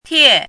“帖”读音
tiè